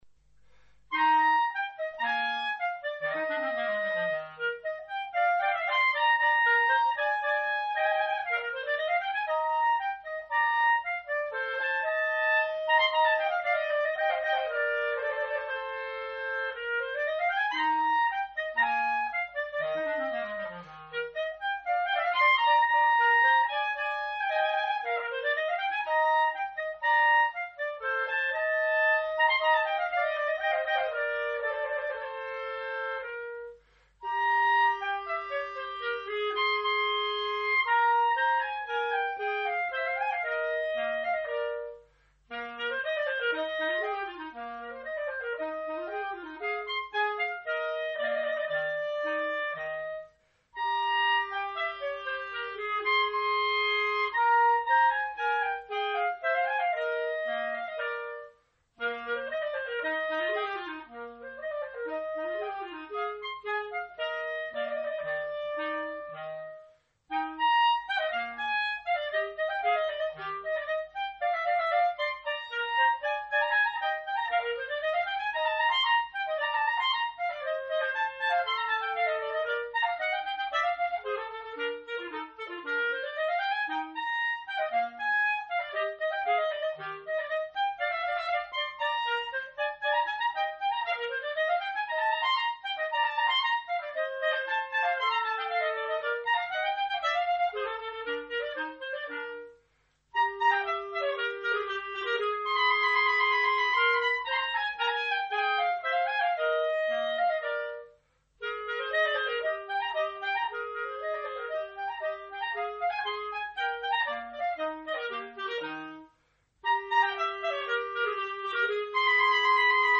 Duo N° 2 opus 5 en fa M.
2e mouvement, Grazioso con variatione (3'20)